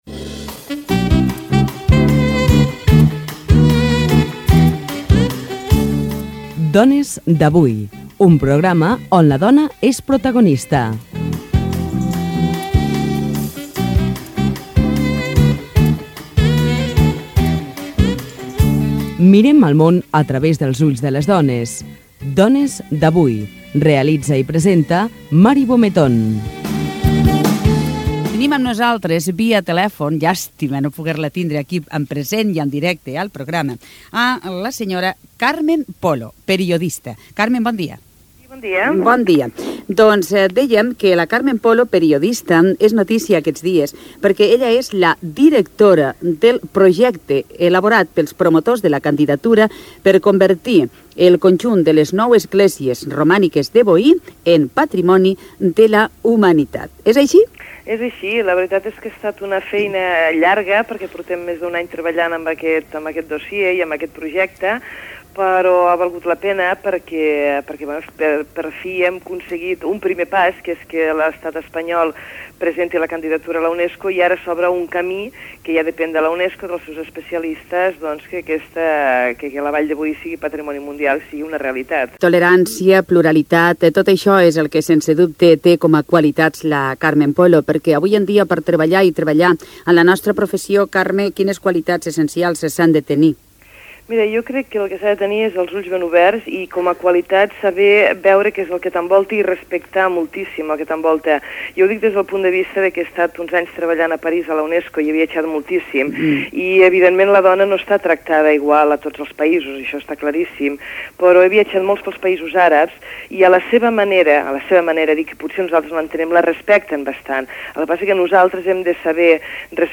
Audios: arxius sonors d’emissores diverses